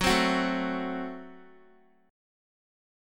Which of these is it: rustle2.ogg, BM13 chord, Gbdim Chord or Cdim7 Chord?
Gbdim Chord